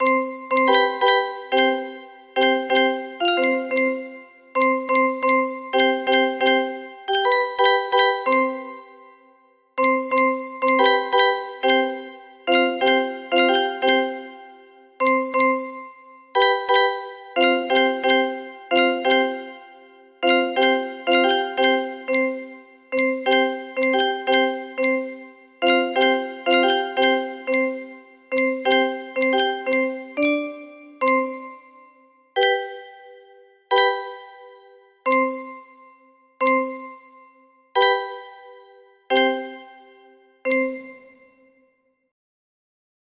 Kronenlied music box melody